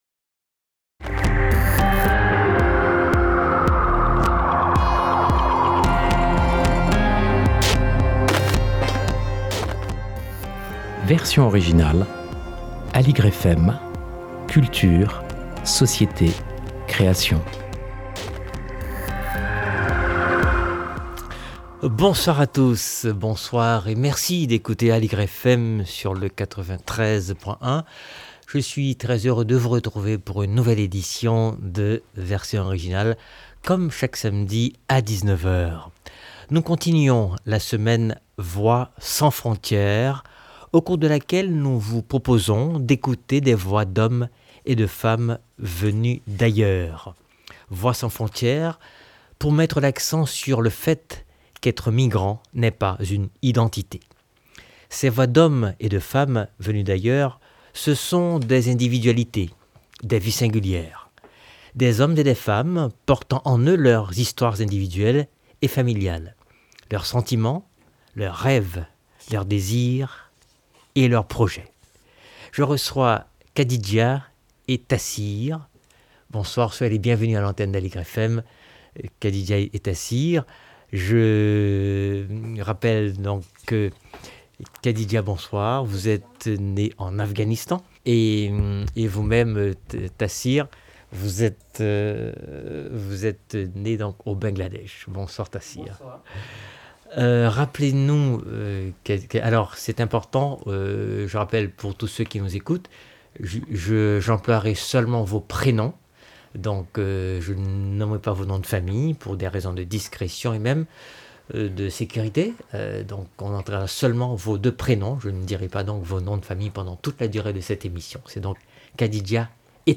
un entretien